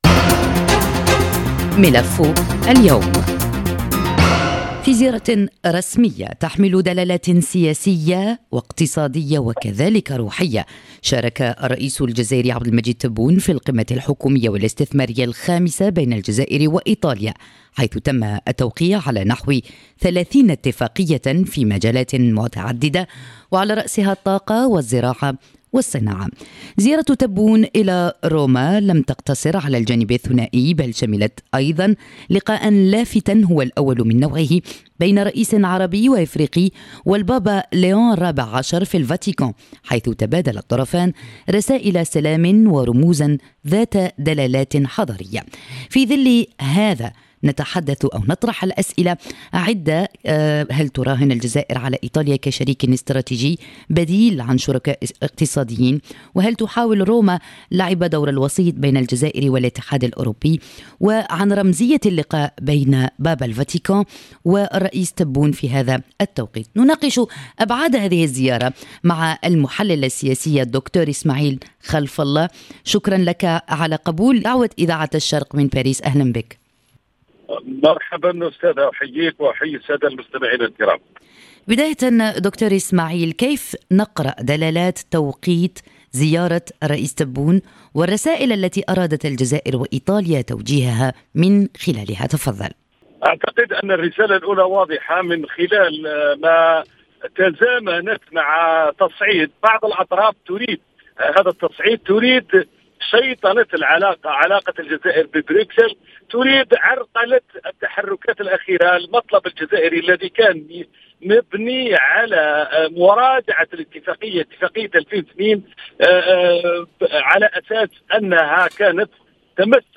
الزيارة حملت أيضًا بُعدًا رمزيًا مهمًا بعد لقاء الرئيس تبون بالبابا ليون الرابع عشر في الفاتيكان، وهو اللقاء الأول لرئيس عربي وأفريقي مع البابا الجديد. حول دلالات هذه الزيارة ورهاناتها حاورنا المحلل السياسي